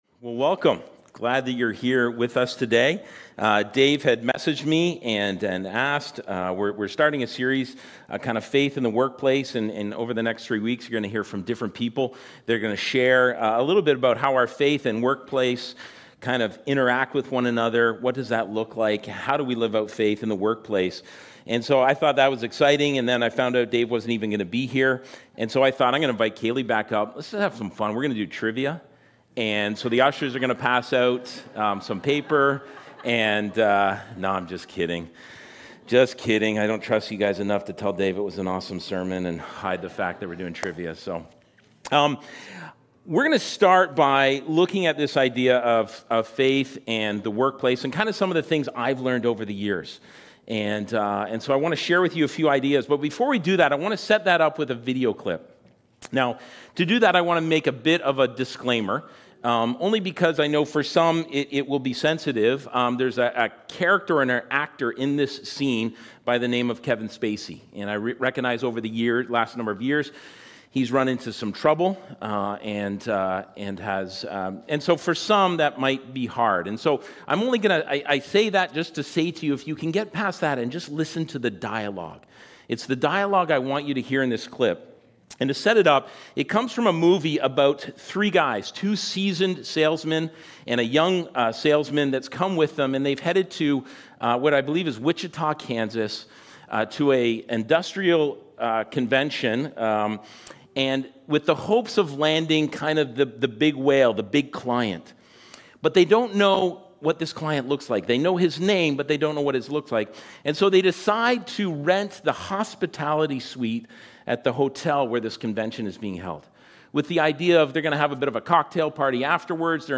Sermons | Westside Church